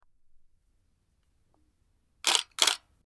Camera Shutter Click 04
Camera_shutter_click_04.mp3